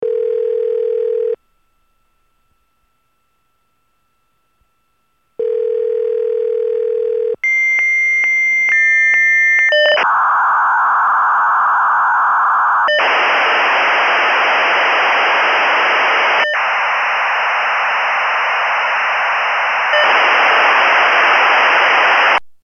Modem Connect 1; 14, 400 Bps Modem Connect; Telephone Rings / Line Noise / Modem Connect. Computer.